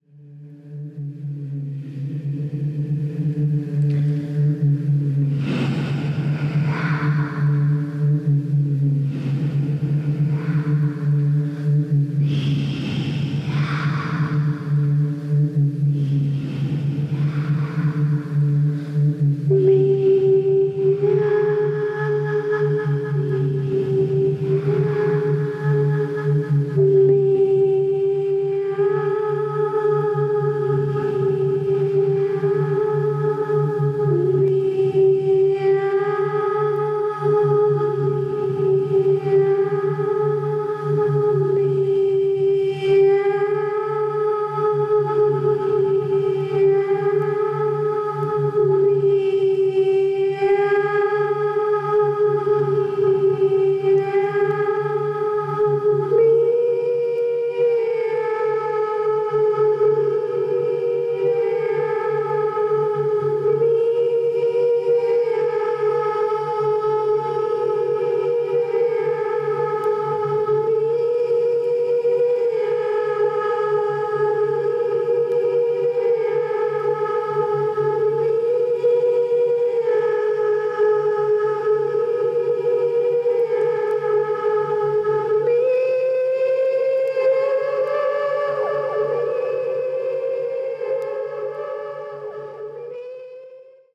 Experimental